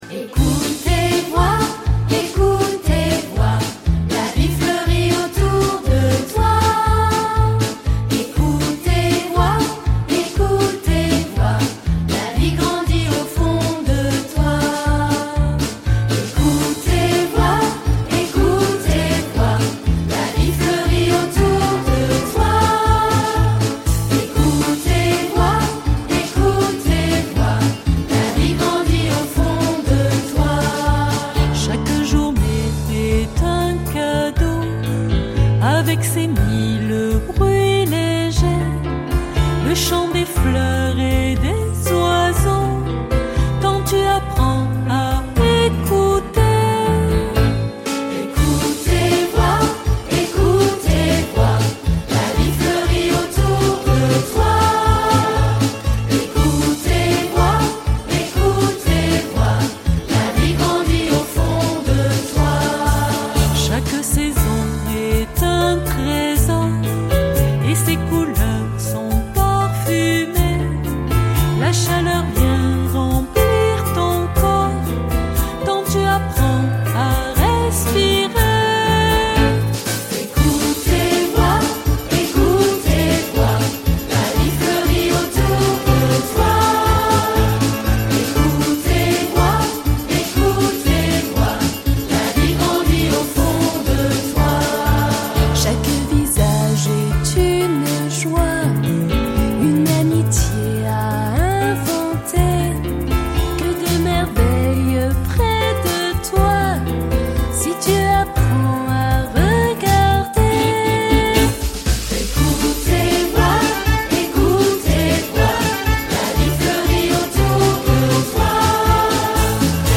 MP3 / Korg / Guitare / Flute et Chant